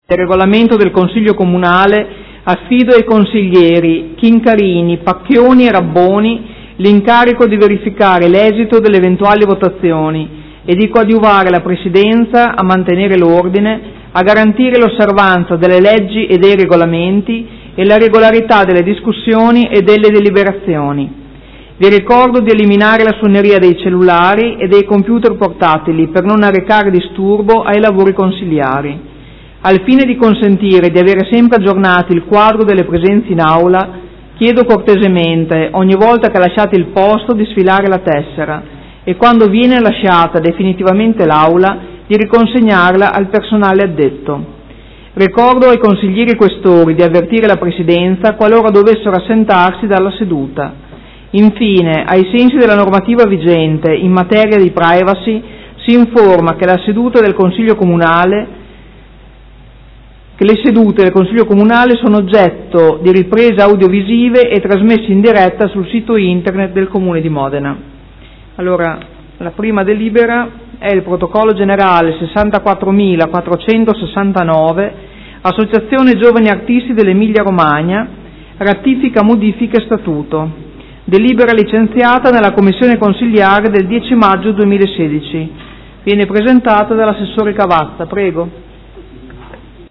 Seduta del 9/06/2016 Apre i lavori del Consiglio Comunale
Presidentessa